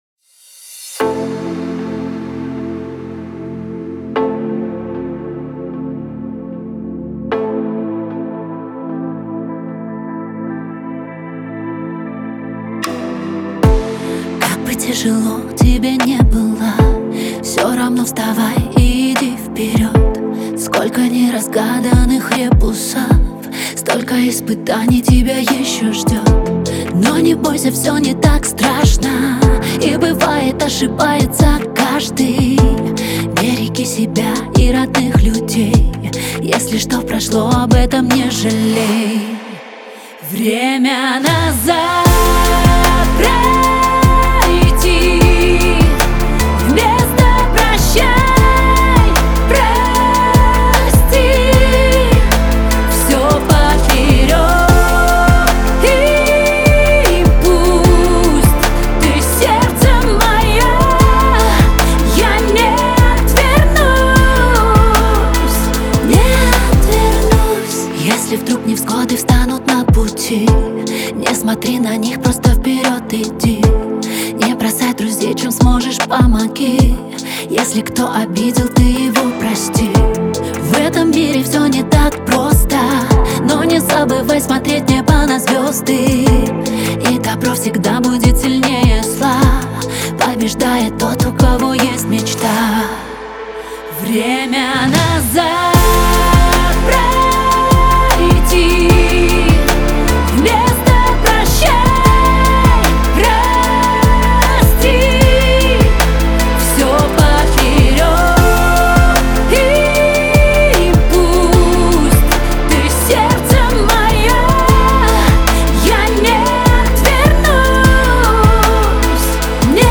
• Качество: 320 kbps, Stereo